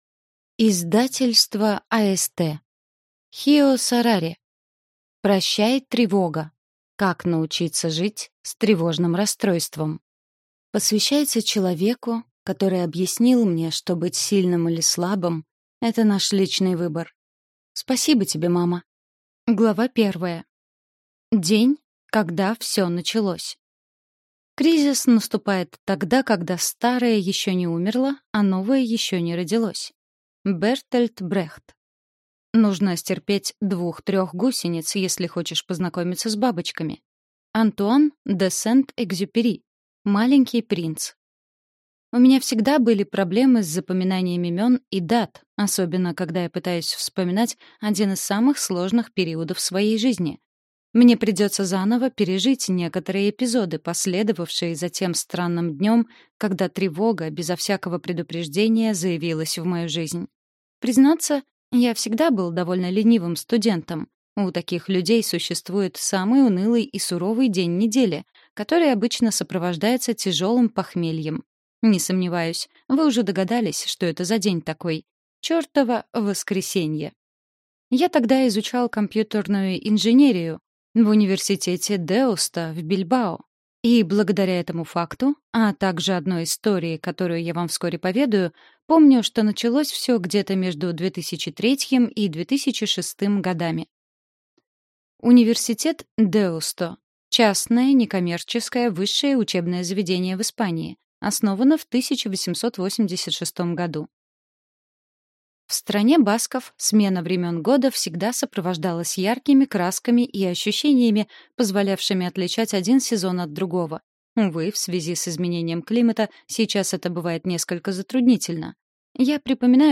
Аудиокнига Прощай, тревога. Как научиться жить с тревожным расстройством | Библиотека аудиокниг